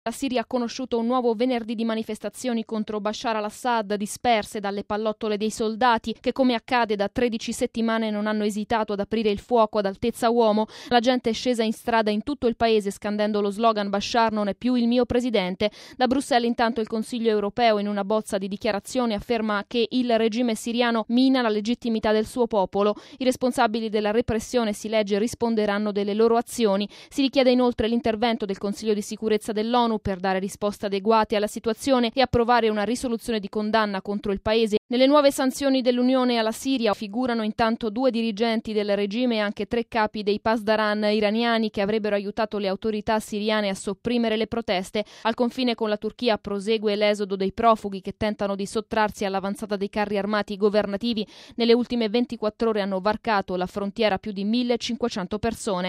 il vertice di Bruxelles ha preso ieri in esame anche la grave situazione in Siria,adottando severe sanzioni nei confronti di Damasco. Intanto, sempre ieri, centesimo giorno della protesta contro il regime del presidente Al Assad, si è registrata una giornata di drammatica violenza con circa 15 manifestanti uccisi dalle forze dell’ordine. Il servizio